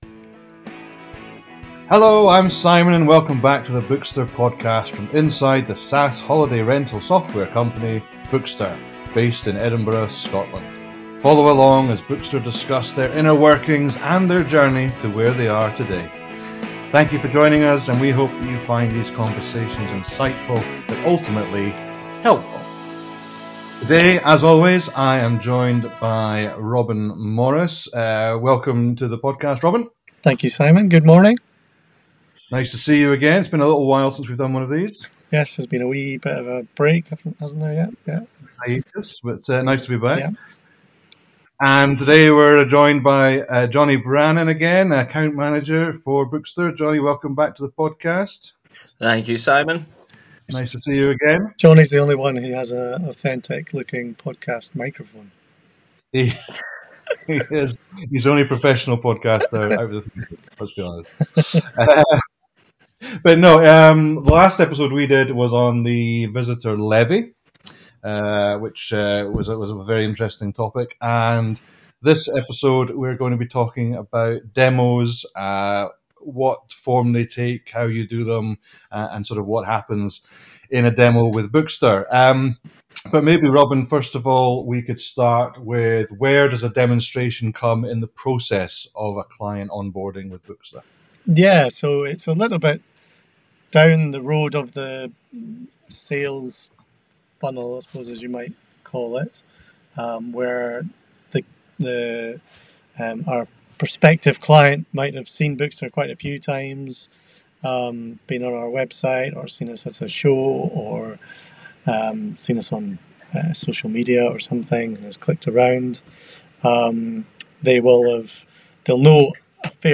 Whether you are a property owner looking to fill your calendar for the 2026 season or a SaaS professional looking to refine your sales funnel, this conversation offers practical advice on using demos to drive genuine business growth.